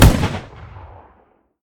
gun-turret-end-3.ogg